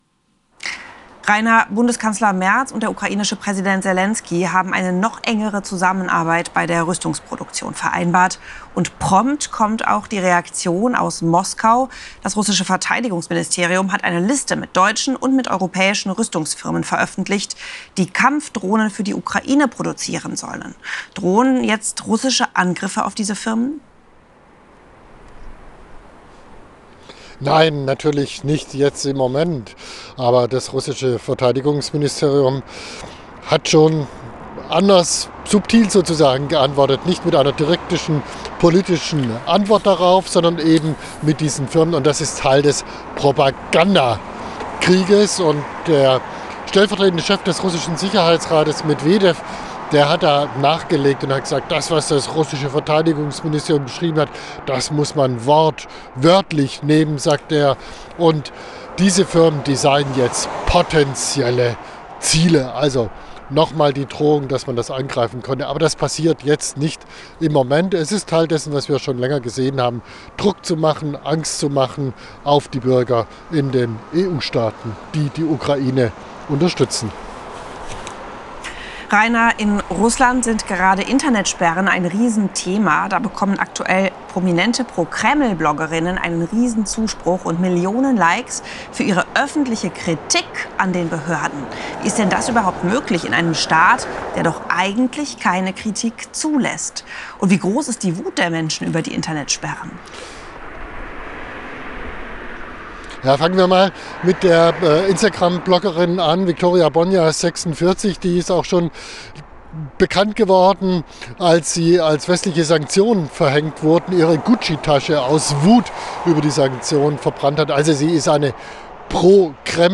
aus Moskau berichtet.